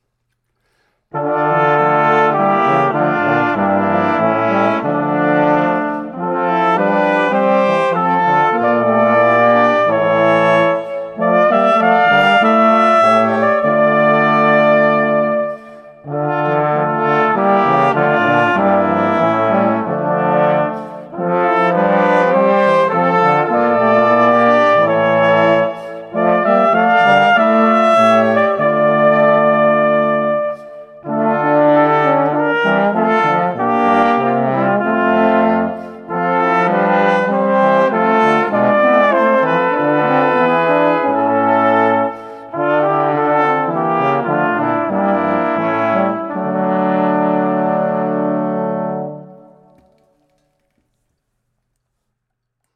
Barocke Kirchenmusik für Blechblasinstrumente
3x Kornett, F-B-Horn, Es-Tuba [0:52]